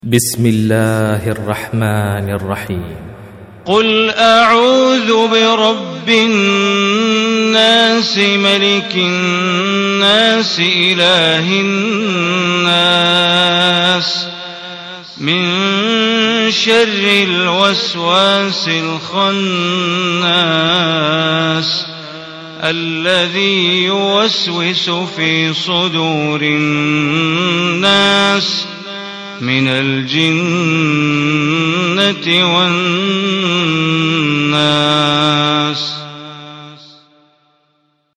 Surah Nas MP3 Recitation by Bandar Baleela
Surah Nas, listen or play online mp3 tilawat / recitation in arabic in the beautiful voice of Sheikh Bandar Baleela. Surah Nas is last surah of Holy Quran.